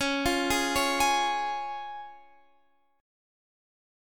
Db Chord
Listen to Db strummed